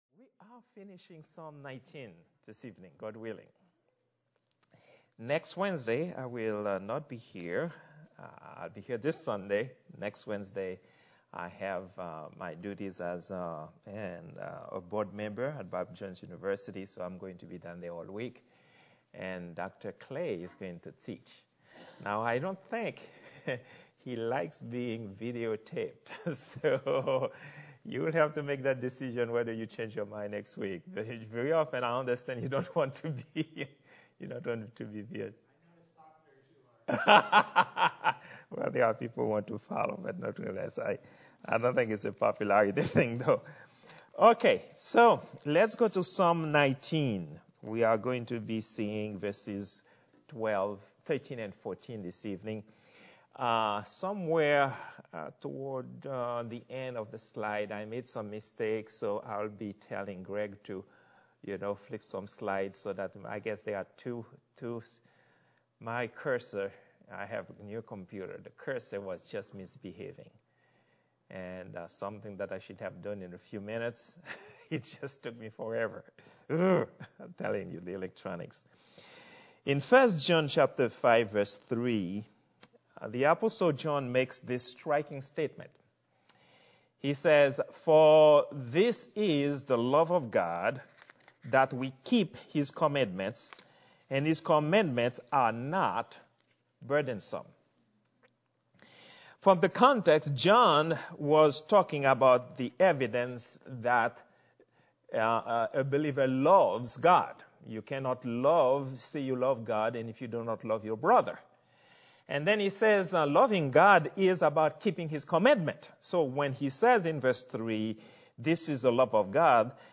Love in the Church Sermon #5 - Beneficence of Love in the Church - Charity - Robinson Baptist
Prayer_meeting_03_23_2022.mp3